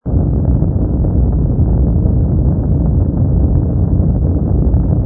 rumble_pi_freighter.wav